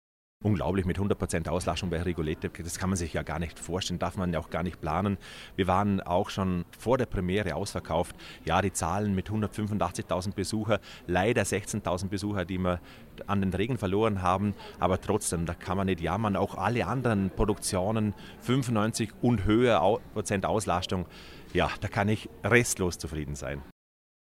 Bilanz-Pressekonferenz News